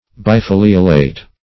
Search Result for " bifoliolate" : The Collaborative International Dictionary of English v.0.48: Bifoliolate \Bi*fo"li*o*late\, a. [Pref. bi- + foliolate.]
bifoliolate.mp3